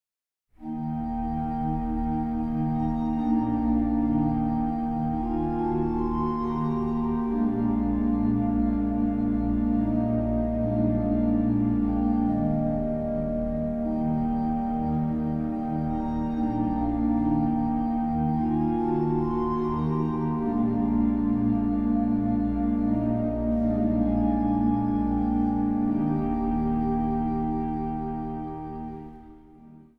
Zang | Gemengd koor